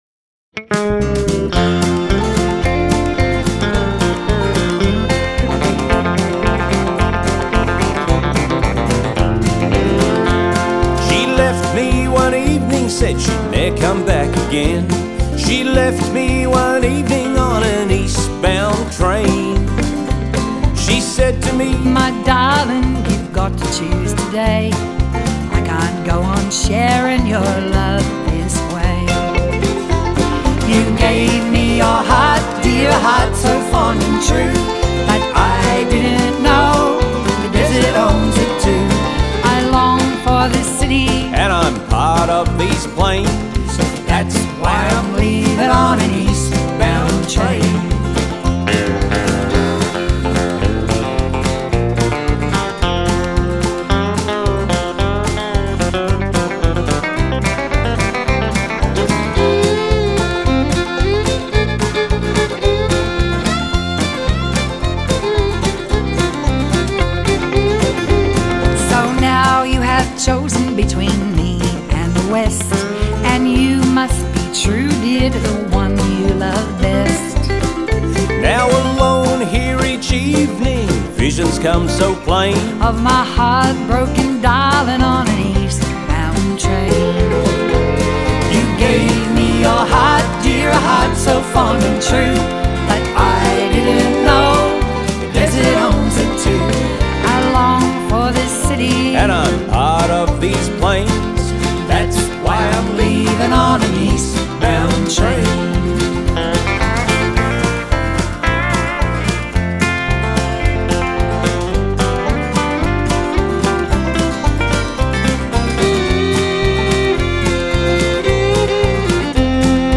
So we give you the very first Bush Ballad/ Bluegrass hybrid.